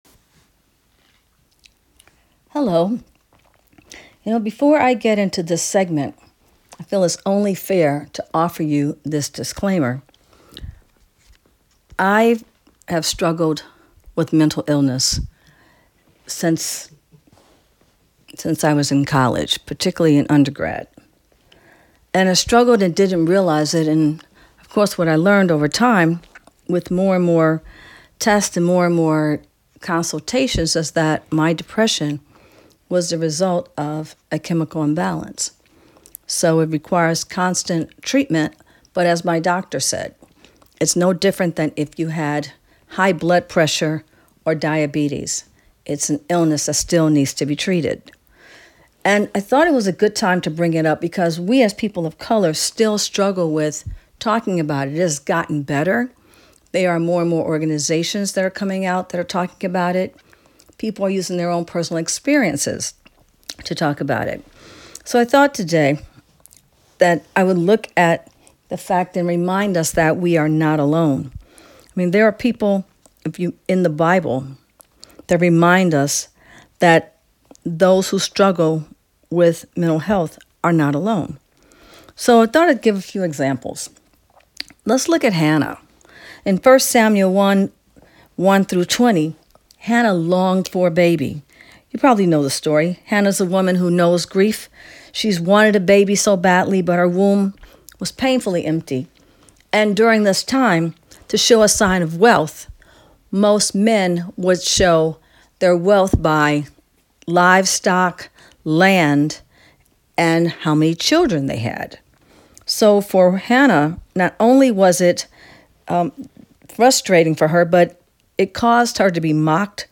Due to an unexpected recording error, we rerecorded the general message on April’s call.
April-2024-Oikeo-Prayer.mp3